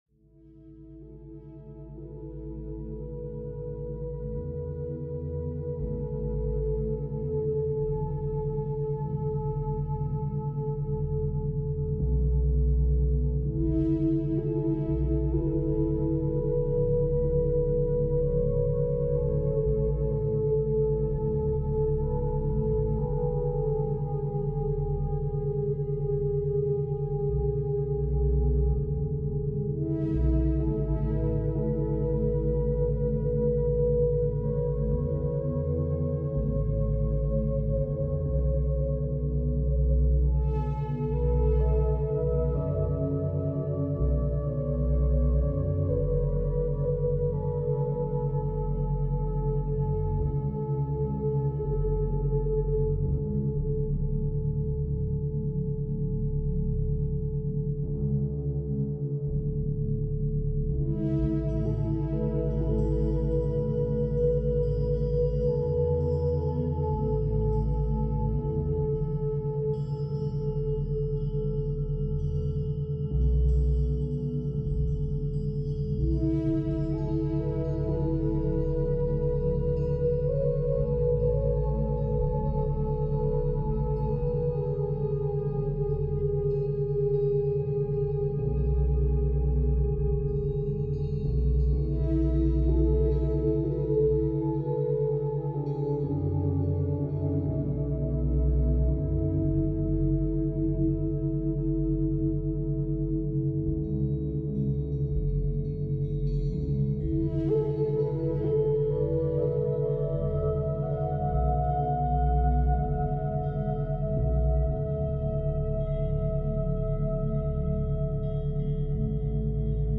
リラクゼーションのためのアルファバイノーラルビート – 11 Hz | 集中、リラクゼーション、創造性